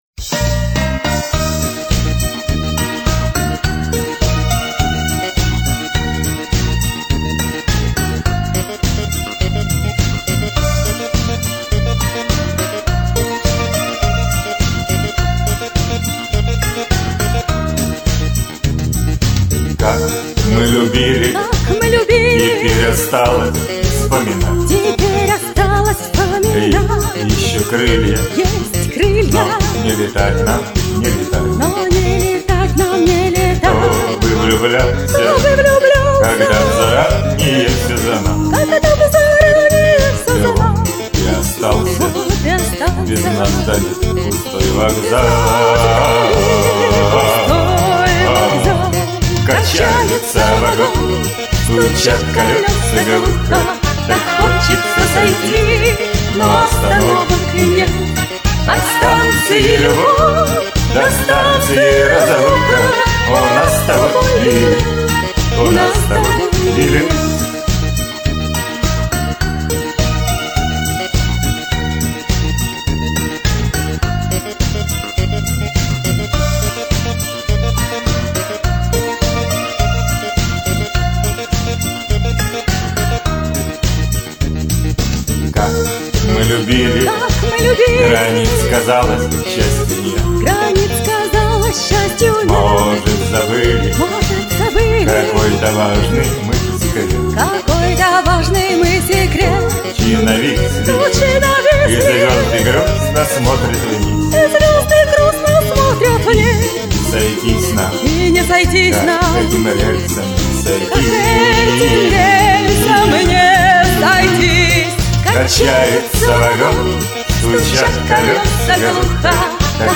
Дуэтное исполнение